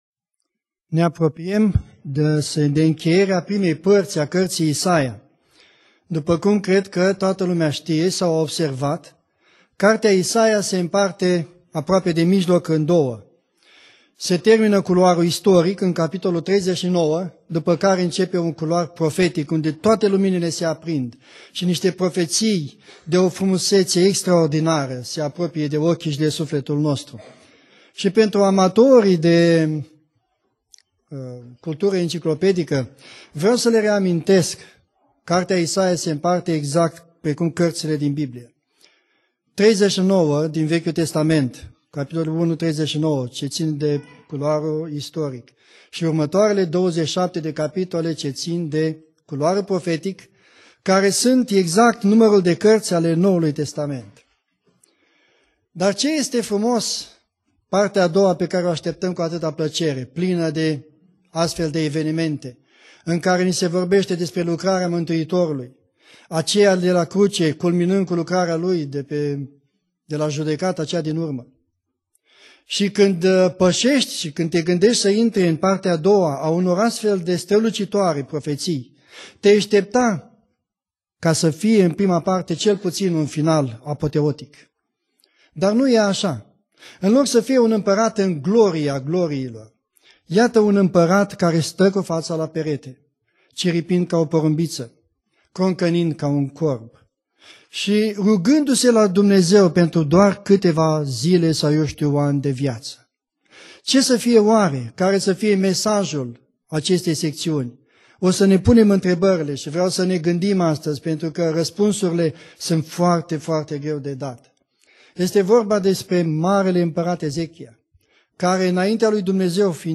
Predica Exegeza - Isaia 38